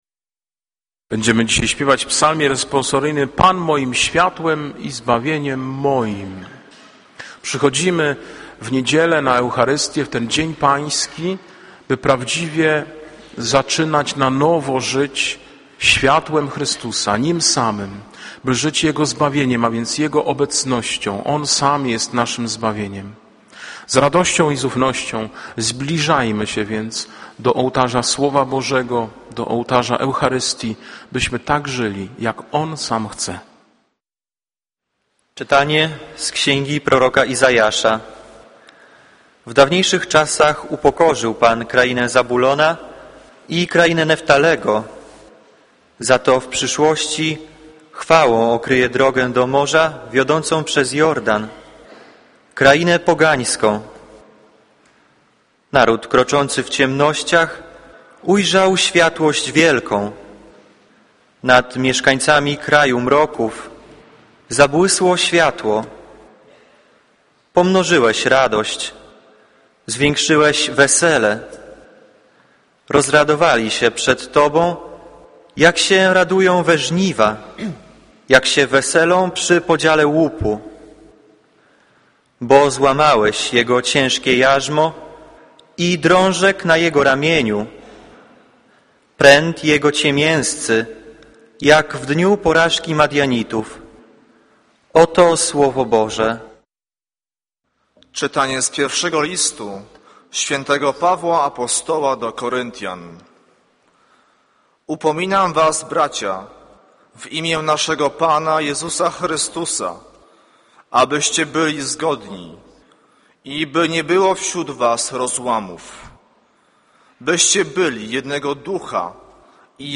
Kazanie z 18 października 2009r.